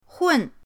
hun4.mp3